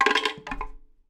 wood_block_hit_9.wav